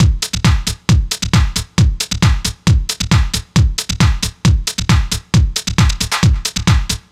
NRG 4 On The Floor 011.wav